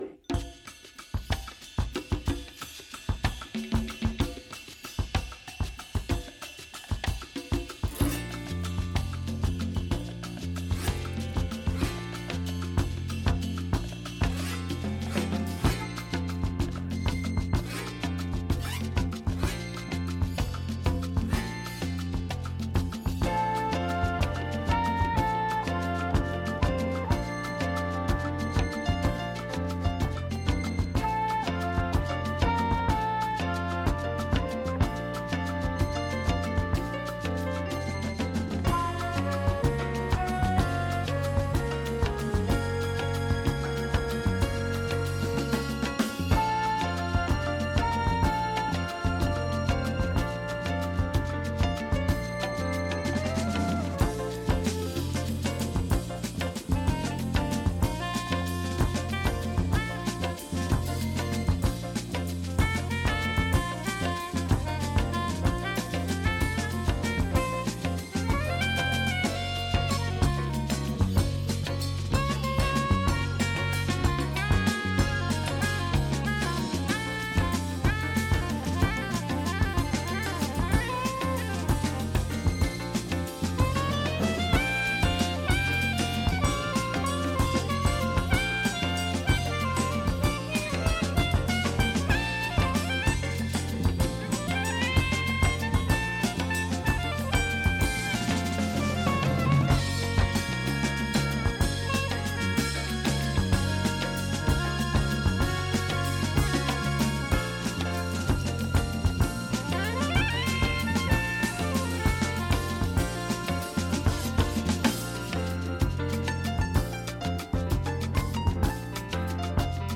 ΜΟΥΣΙΚΗ